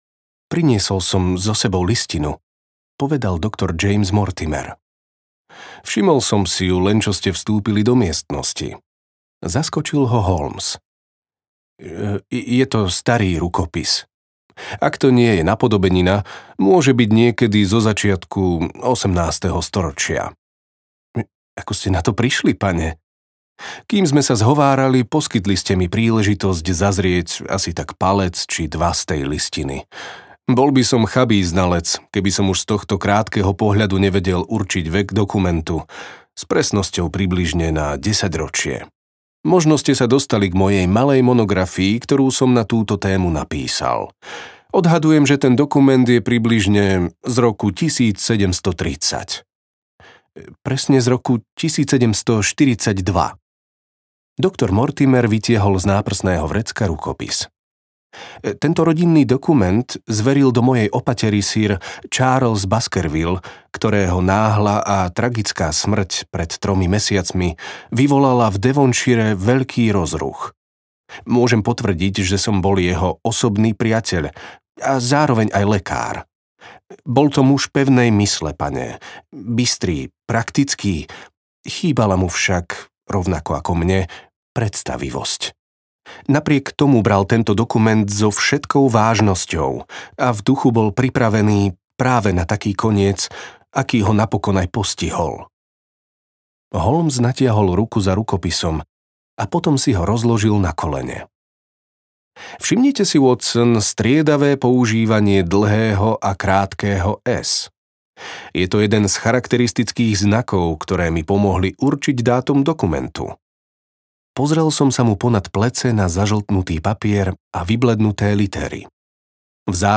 Sherlock Holmes 3: Pes rodu Baskervillovcov audiokniha
Ukázka z knihy